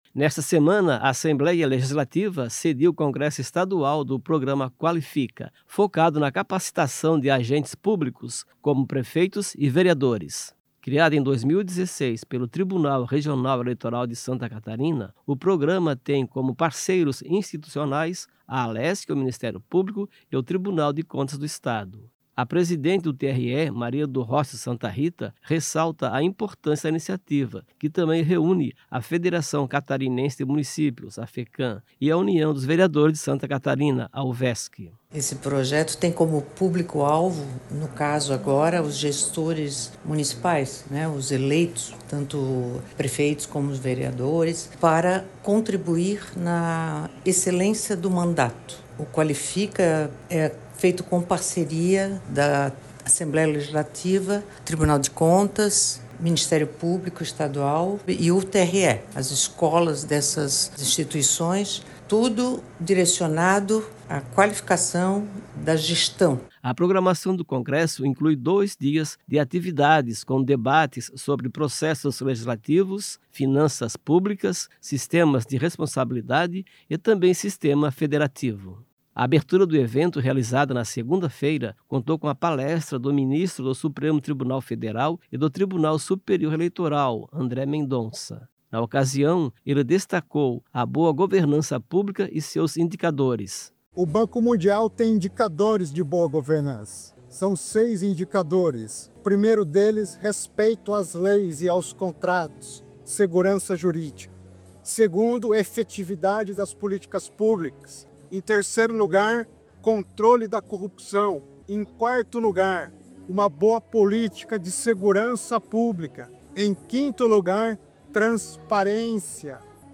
Entrevistas com:
- deputado Júlio Garcia (PSD), presidente da Alesc;
- Maria do Rocio Santa Ritta, presidente do TRE;
- André Mendonça, Ministro do STF e TSE.